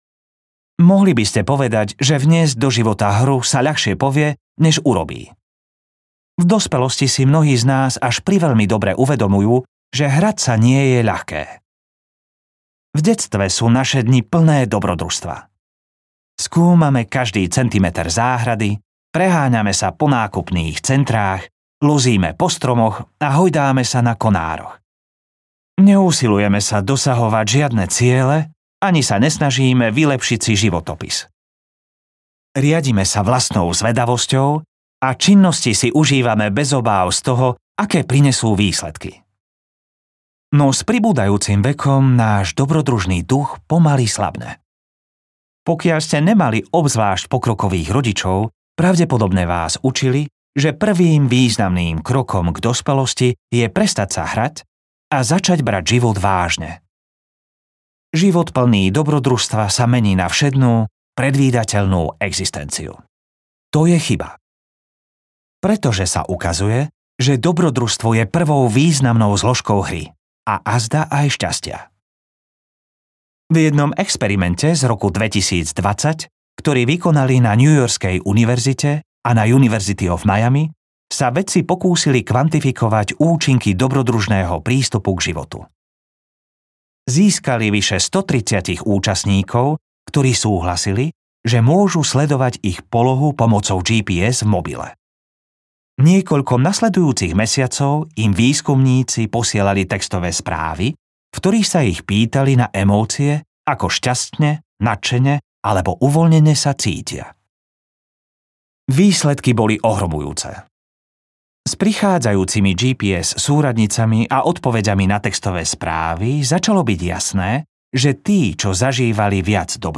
Audiokniha Produktivita bez starostí - Ali Abdaal | ProgresGuru